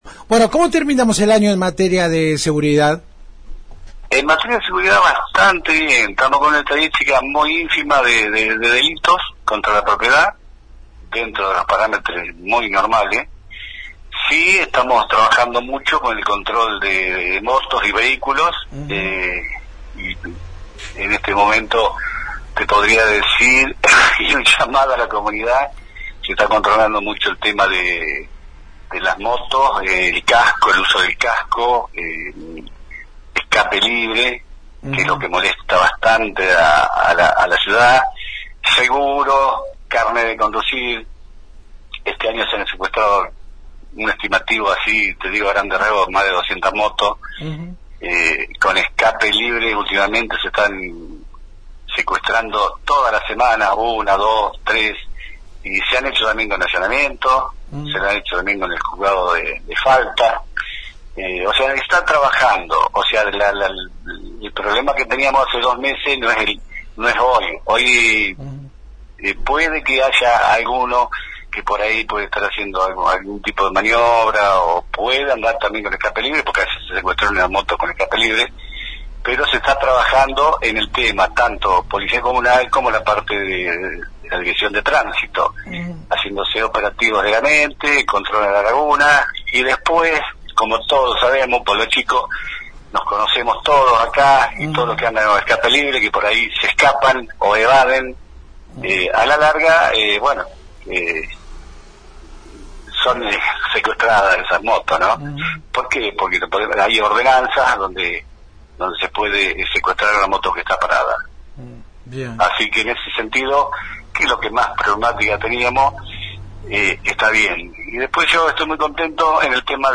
El subsecretario de Protección Ciudadana realizó en la 91.5 un balance del 2025 en nuestra ciudad en materia de seguridad.